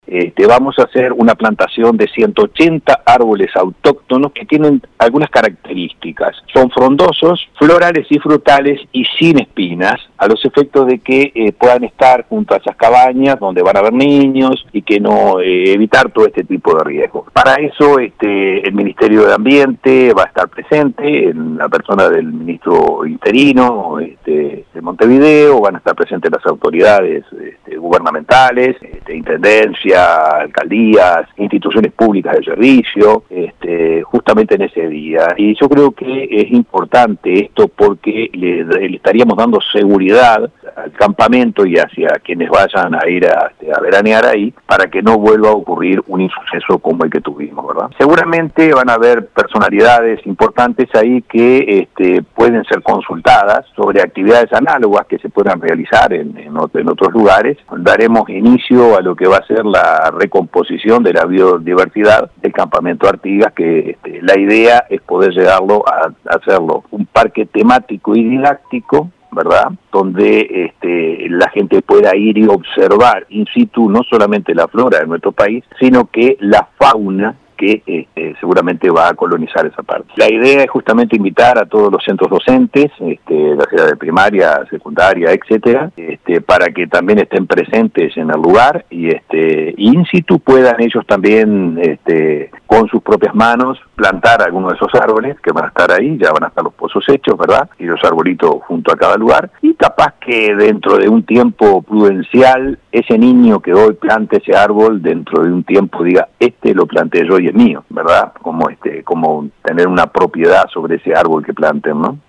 A continuación, escuchamos al fiscal de ONG ambientalistas